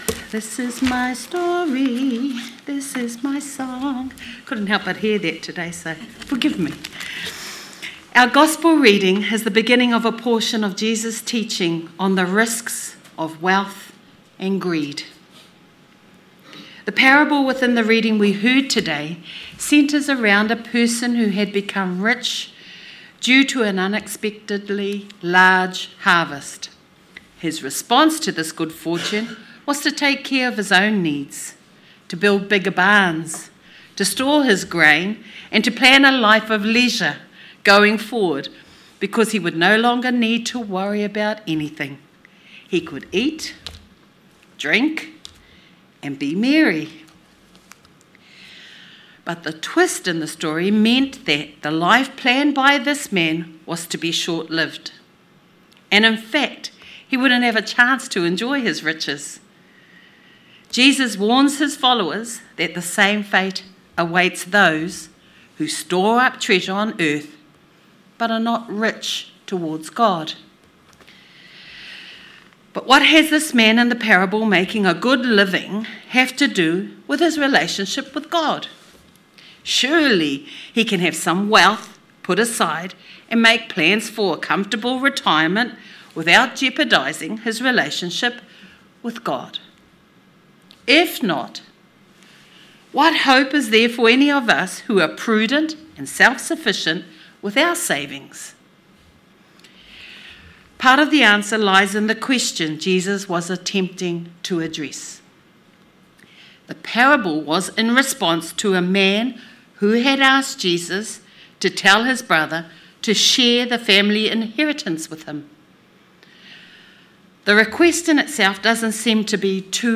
Sermon 3rd August 2025 – A Lighthouse to the community